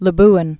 (lə-bən, läb-än)